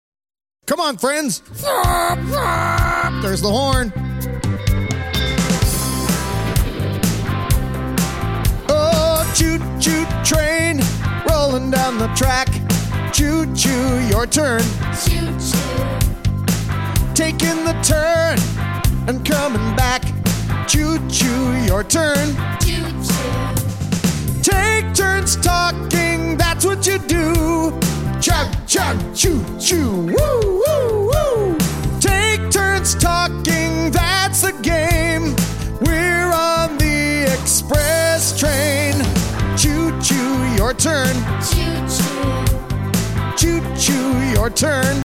-Kids and adults singing together and taking verbal turns